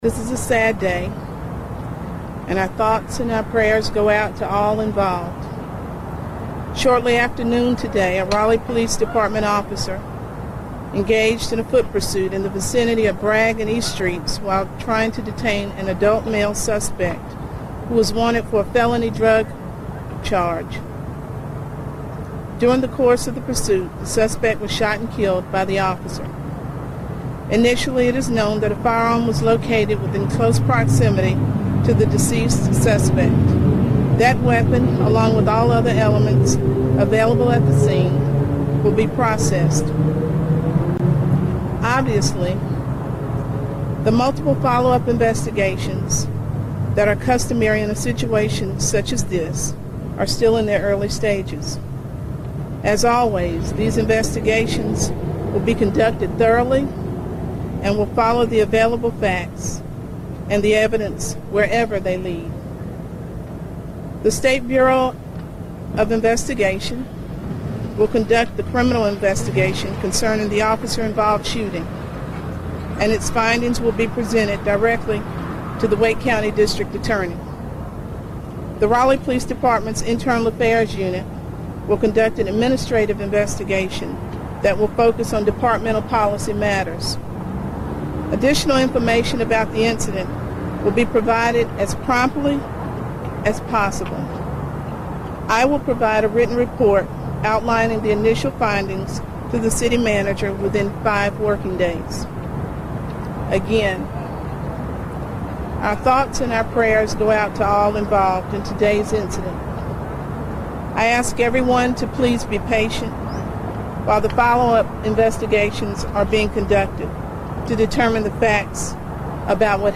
Raleigh Police Chief Cassandra Deck-Brown confirmed the death in a press conference Monday afternoon.
Raleigh PD Statement.mp3